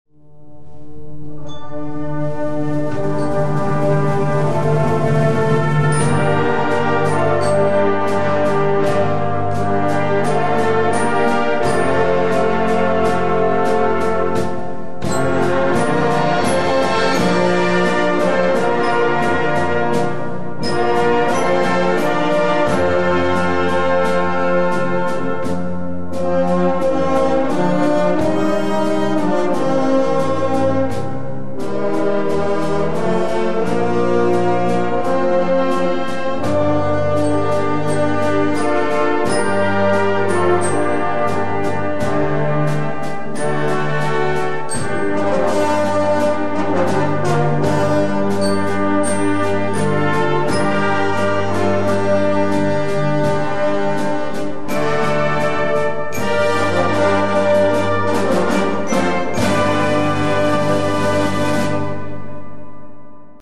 Livemitschnitt von Konzerten
Damit ihr euch überzeugen könnt, wie zum Beispiel ein Konzert eines Musikvereins, aufgenommen im Theater in Reichenau im November 2003, klingt, habe ich ein paar Ausschnitte als Demos links angeführt!